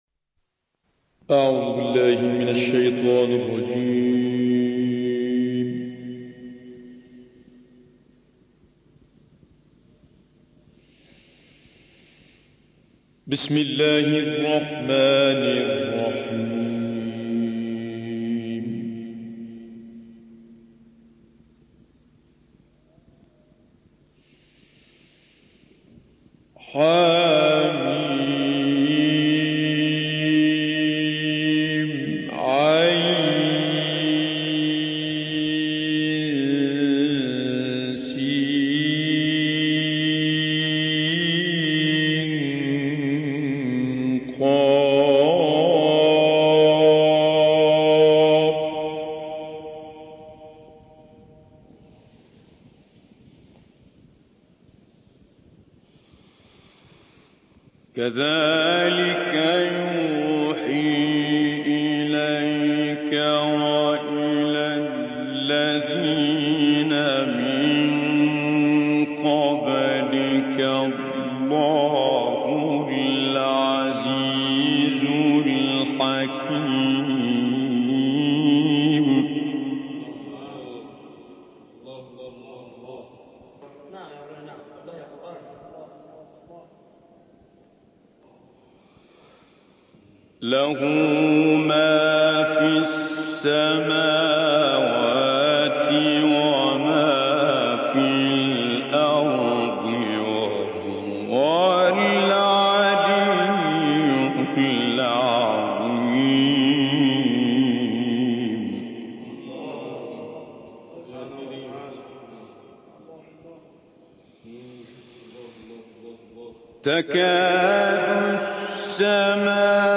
تلاوت سوره نساء با صدای سیدمتولی عبدالعال+ دانلود/ پرورش روح جهاد در مسلمانان
گروه فعالیت‌های قرآنی: قطعه‌ای زیبا از تلاوت استاد سیدمتولی عبدالعال از آیات ۶۹ - ۸۰ سوره نساء ارائه می‌شود.